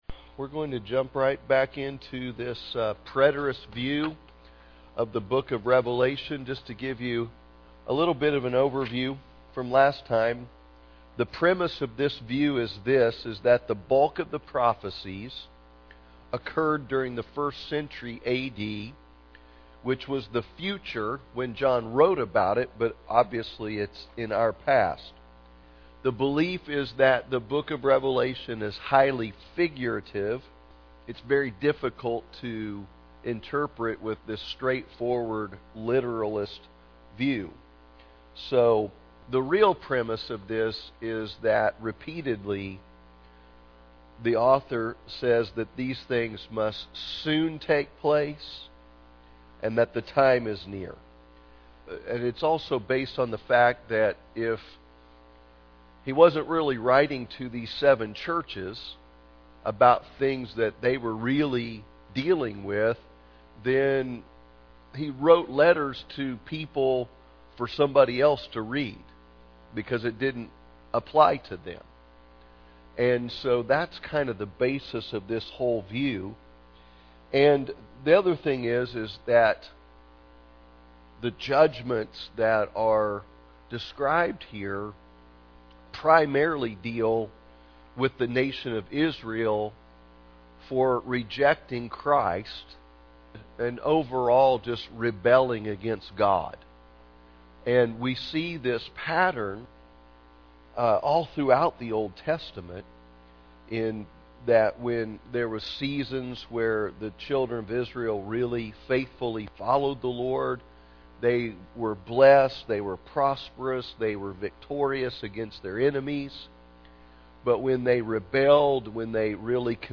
The Four Views of Revelation Wednesday Evening Service